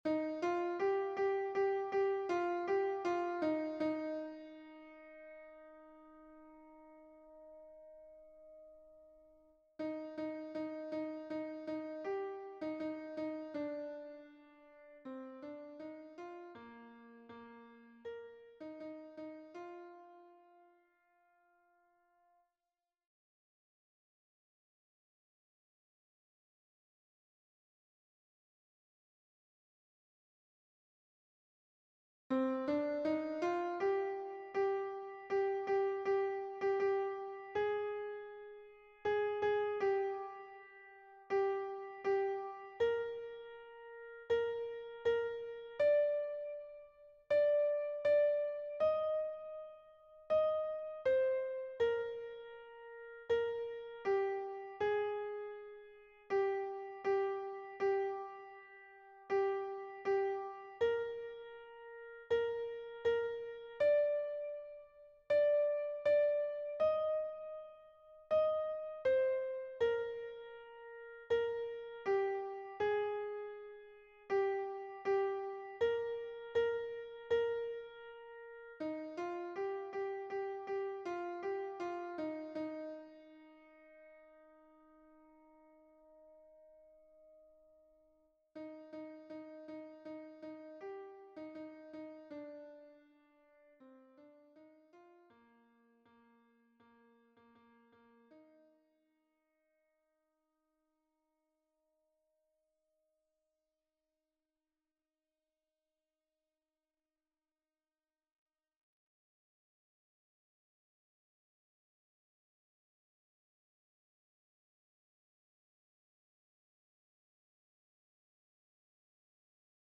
MP3 version piano
Mezzo Soprano